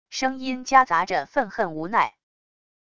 声音夹杂着愤恨无奈wav音频